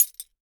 GLASS_Fragment_13_mono.wav